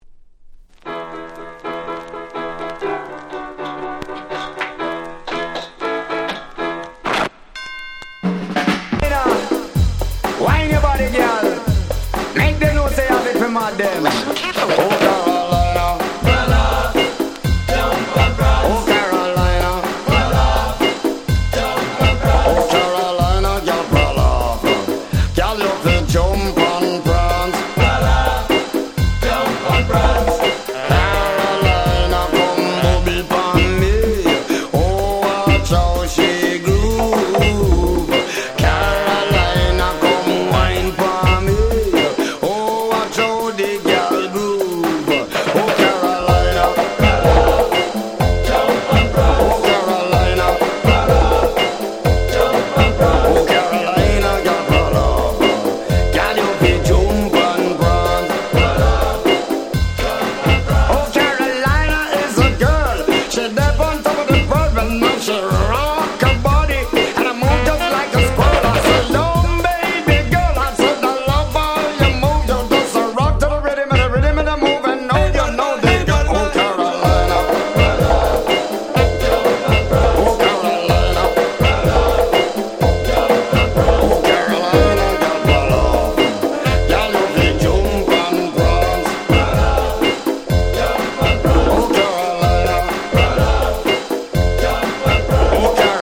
93' Reggae Classics !!
緩〜いBeatに彼のFlowが堪りません！
Dancehall